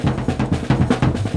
JAZZ BREAK24.wav